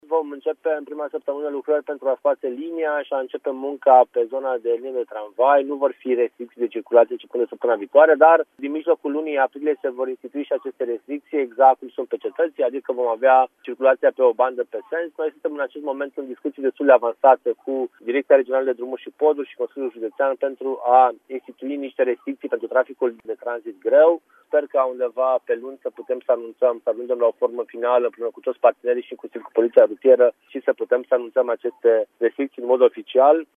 Se va lucra la reabilitarea rețelei de apă canal și a liniilor de tramvai și a tramei stradale, dar în prima săptămână nu vor fi restricții de circulație, a precizat, la Radio Timișoara, viceprimarul Ruben Lațcău.